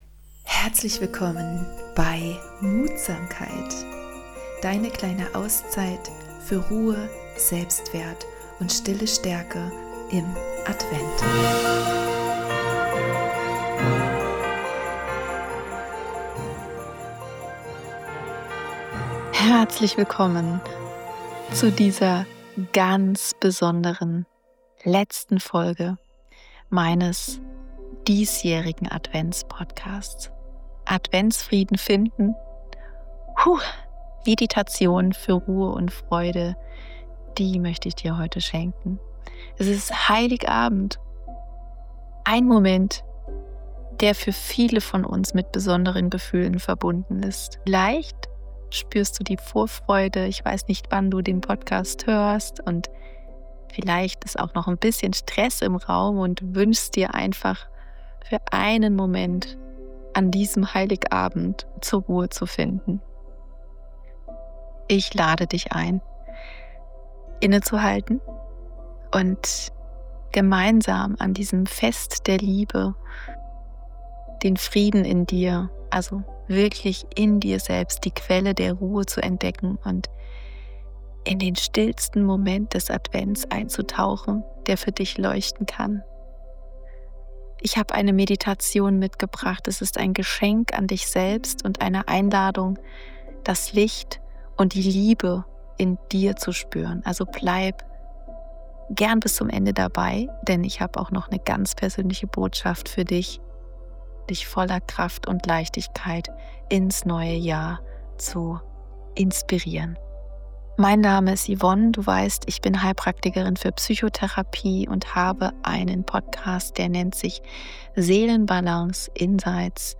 In einer besinnlichen Meditation werde ich dich begleiten, die